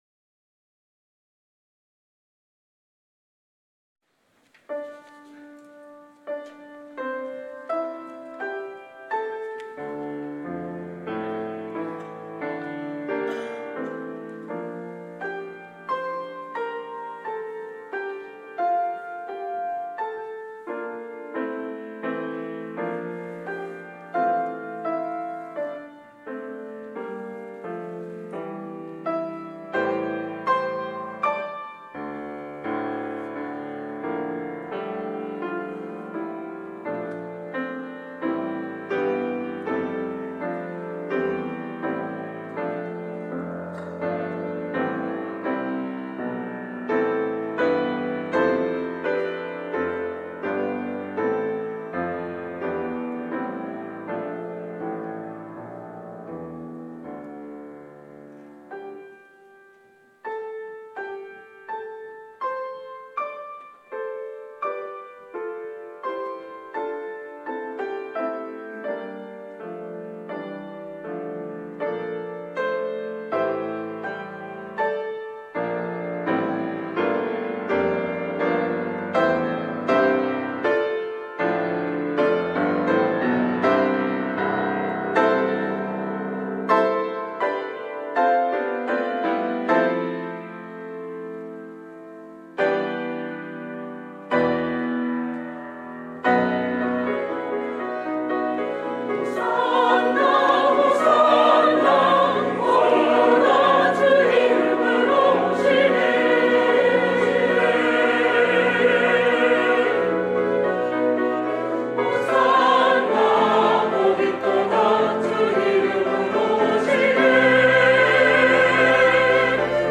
종려주일 찬송